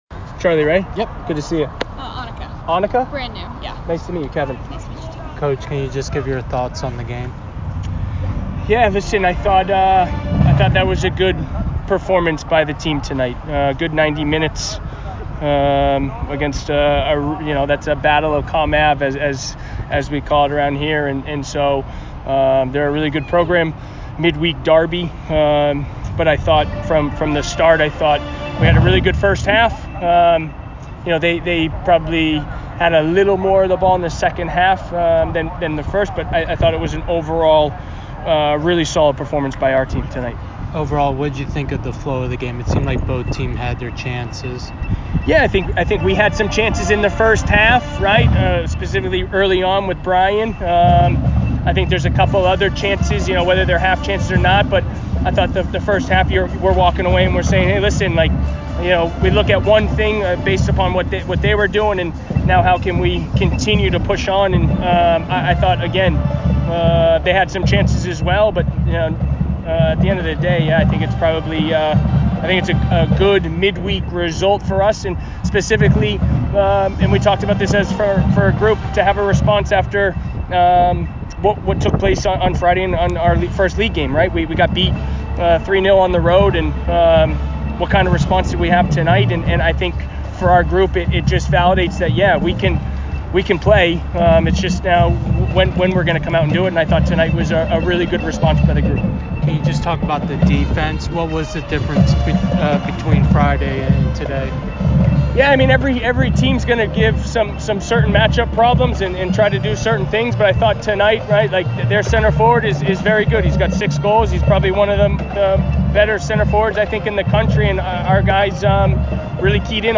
BC Postgame Interview